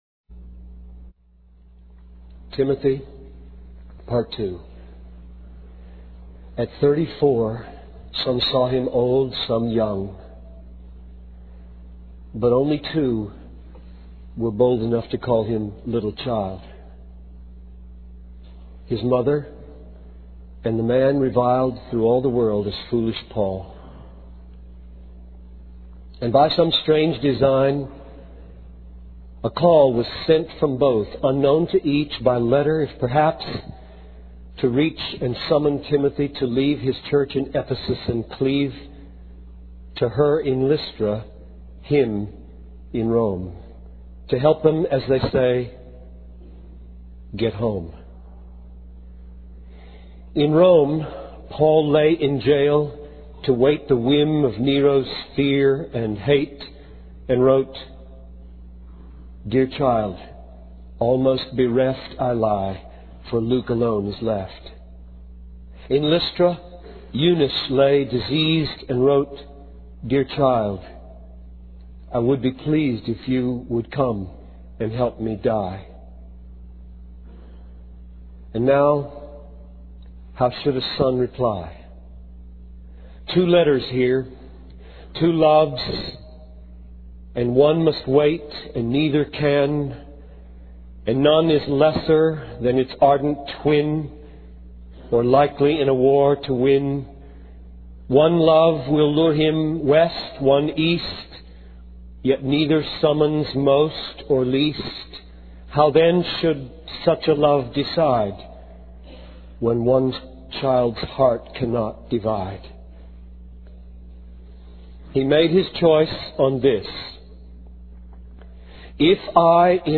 In this sermon transcript, the speaker reflects on the journey of faith and the importance of holding onto the truth of God's word. The speaker recounts a story of Timothy, who faced challenges and doubts while traveling to Rome.